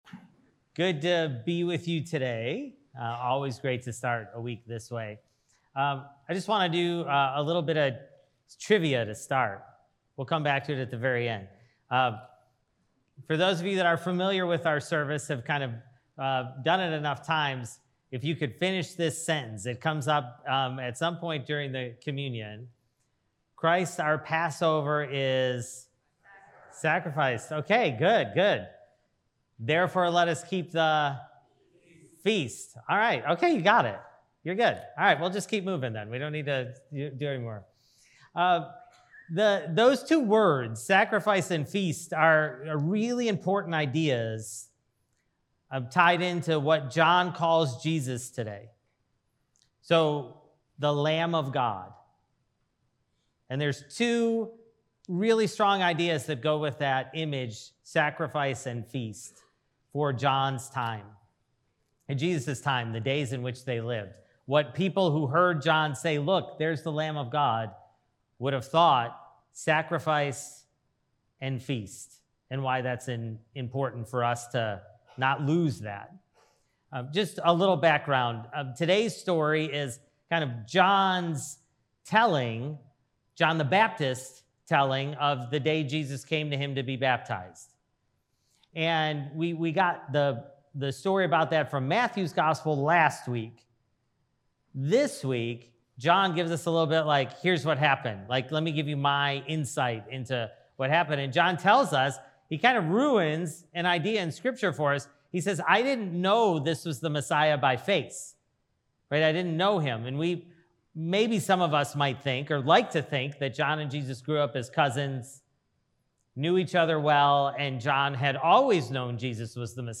Sermon: Sacrifice and Feast (John 1:29-42)